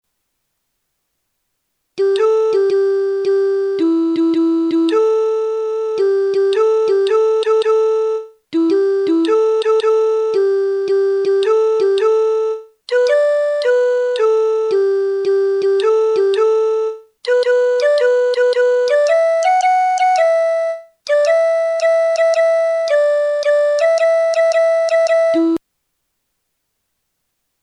下の節回しは私のイメージの中の京言葉アクセントで付けてみたいい加減なものですし、そればかりでなく歌詞の方も古い記憶に頼ったいい加減なもの、ということをおことわりしておきます。